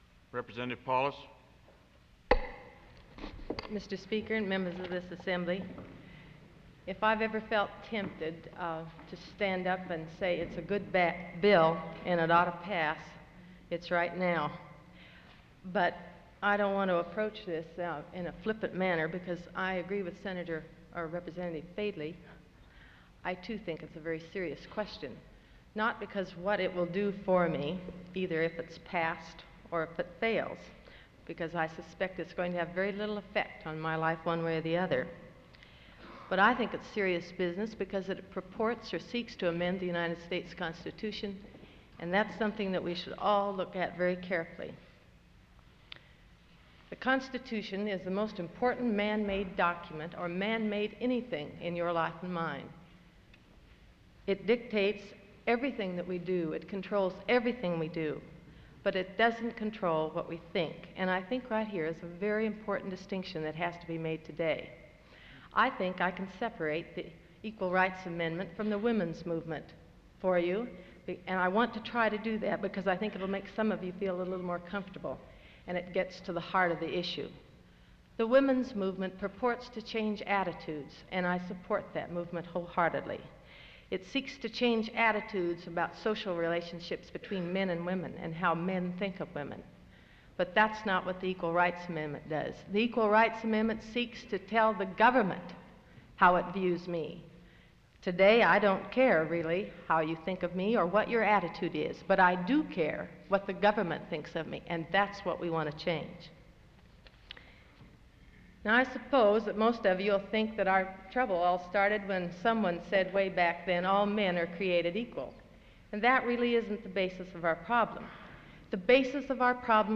Norma Paulus House Floor Speech Feb 8 1973